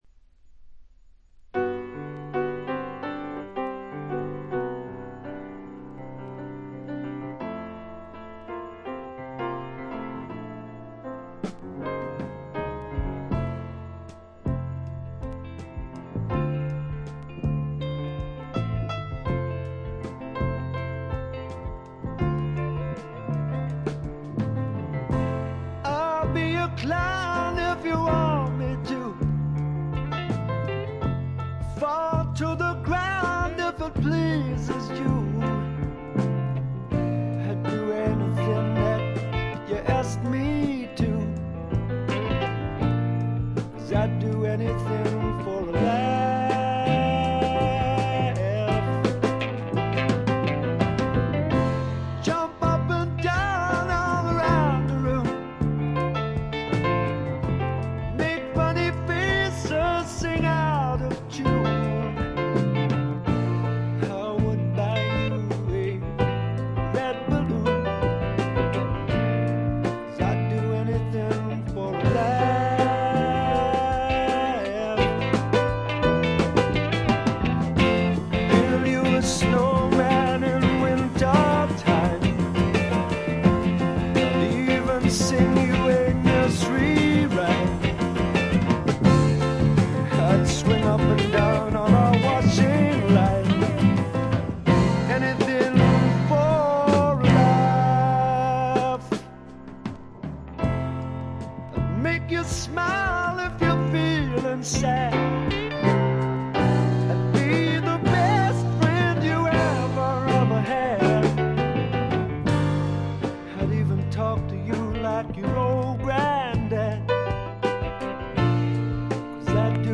静音部で微細なチリプチが少し認められる程度でほとんどノイズ感無し。
ちょいと鼻にかかった味わい深いヴォーカルがまた最高です。
試聴曲は現品からの取り込み音源です。